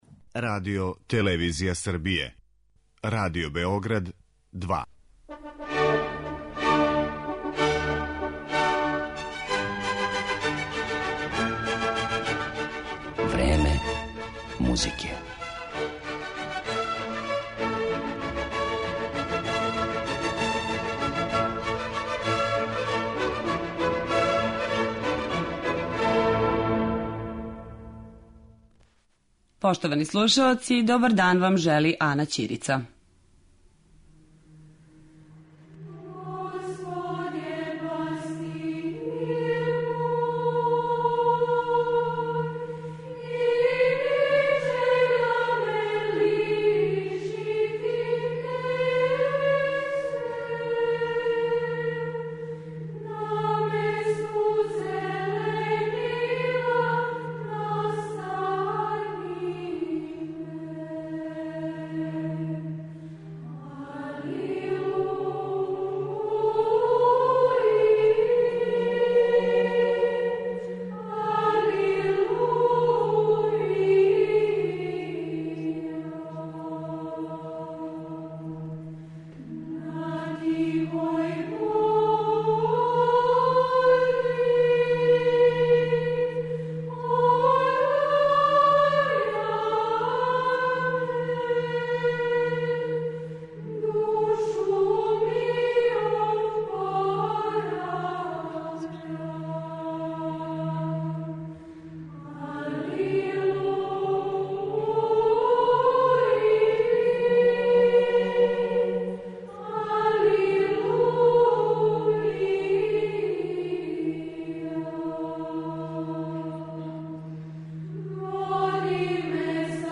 Данашњу емисију реализујемо из Ниша, града у коме се вечерас завршава Пети међународни фестивал хорске духовне музике 'Музички едикт'.